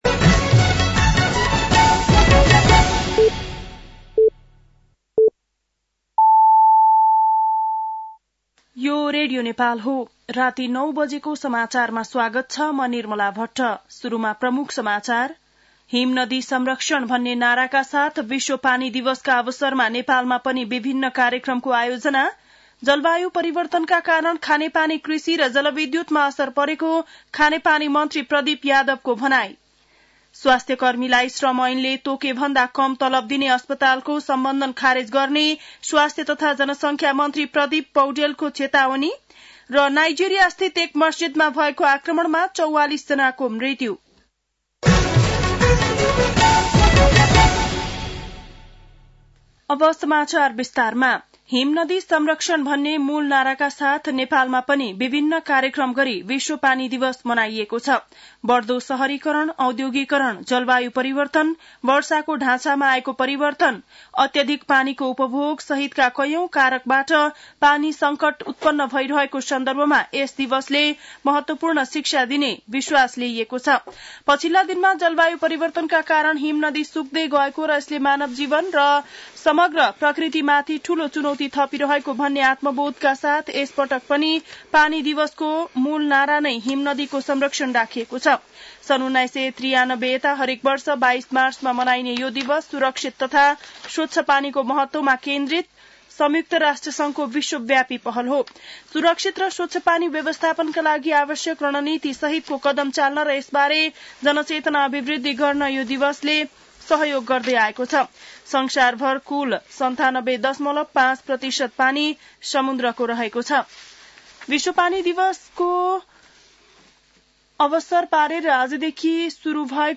बेलुकी ९ बजेको नेपाली समाचार : ९ चैत , २०८१
9-PM-Nepali-NEWS-12-09.mp3